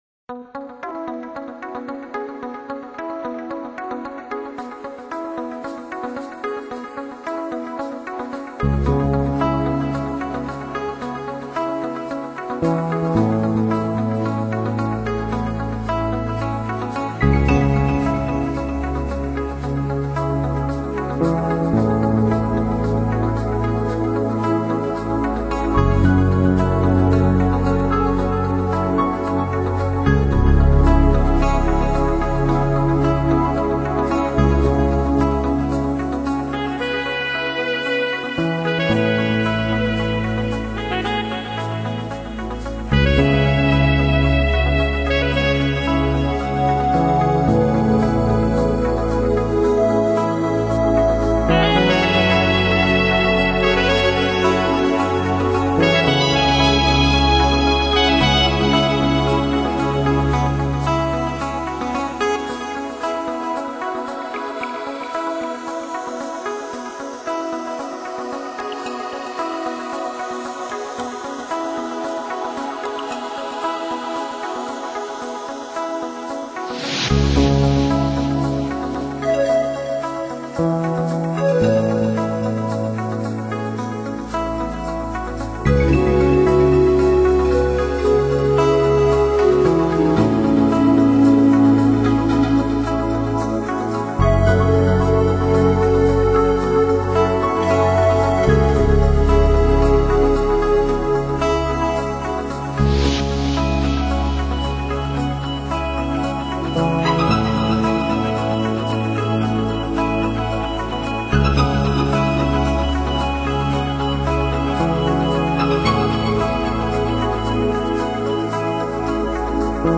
主题鲜明的钢琴演奏专辑，琢磨出钢琴静谧、激情的双面美感